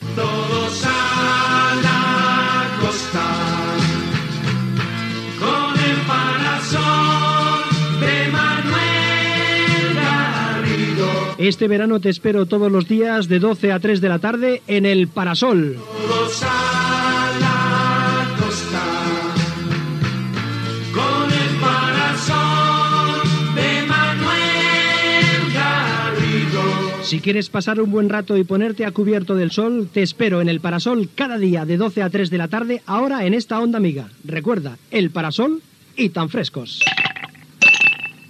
Sintonia del programa i presentació